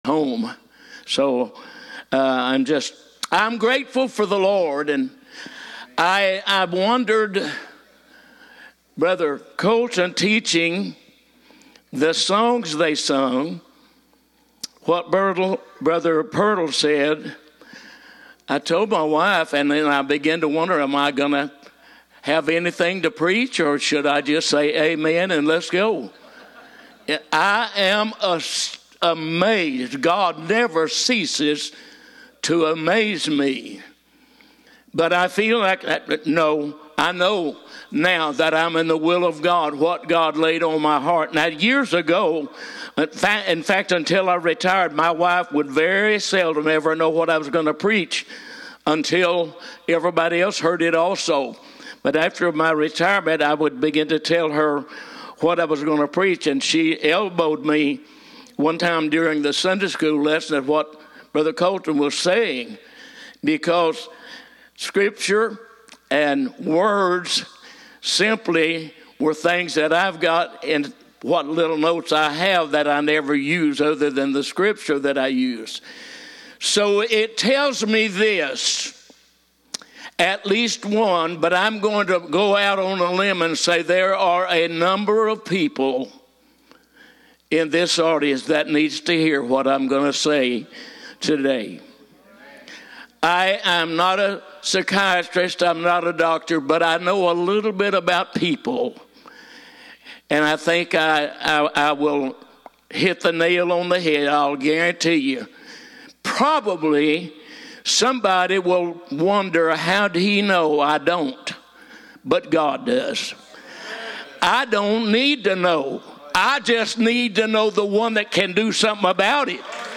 2 Corinthians 12:7-9 Guest Speaker March 22